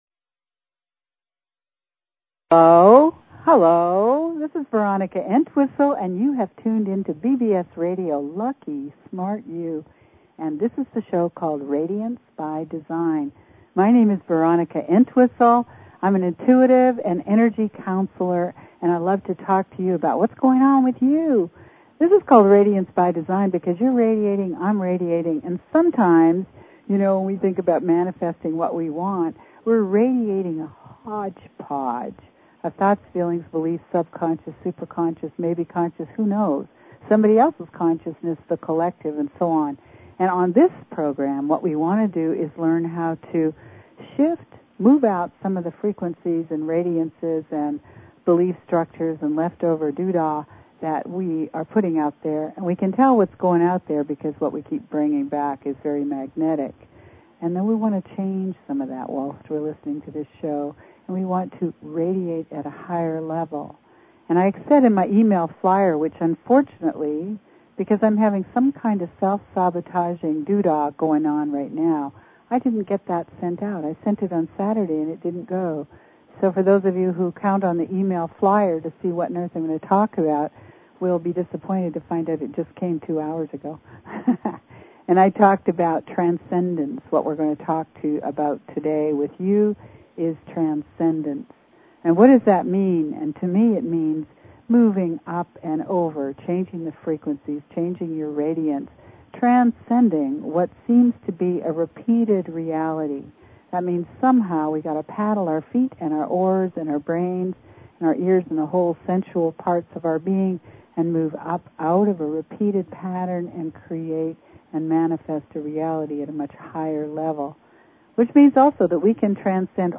Talk Show Episode, Audio Podcast, Radiance_by_Design and Courtesy of BBS Radio on , show guests , about , categorized as
Radiance By Design is specifically tailored to the energies of each week and your calls dictate our on air discussions.